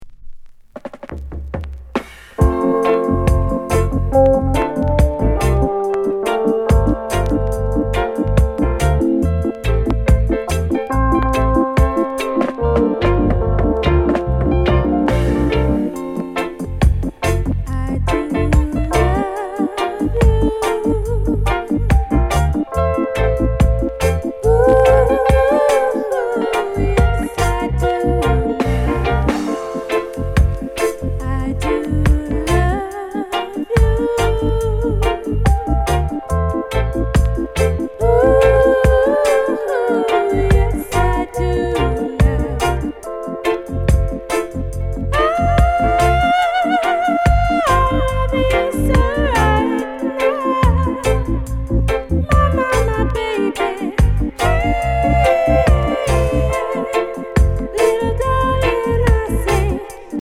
LOVERS ROCK